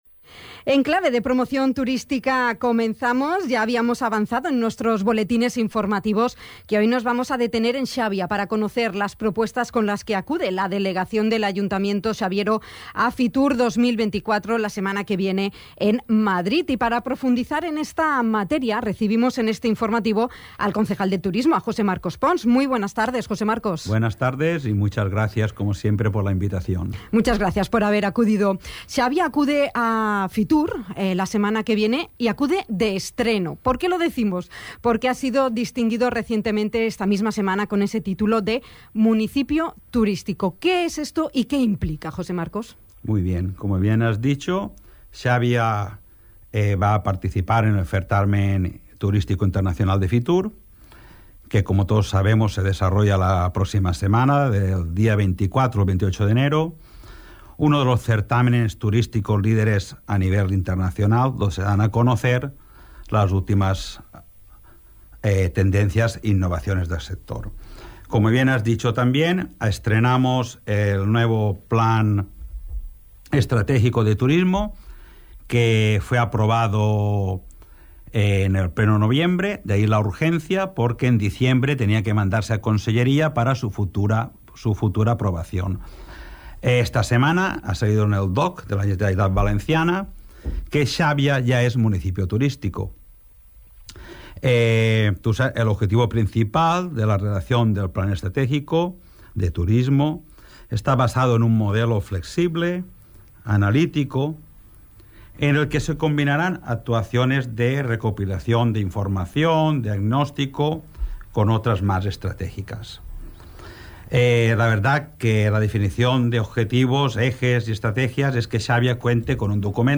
Con este motivo, ha visitado Dénia FM, el concejal de Turismo del Ayuntamiento de Xàbia, José Marcos Pons, para dar a conocer la promoción en la que está trabajando este departamento de cara a su presencia y participación en la que está considerada uno de los certámenes turísticos líderes a nivel internacional.
Entrevista-Jose-Marcos-Pons-Fitur-.mp3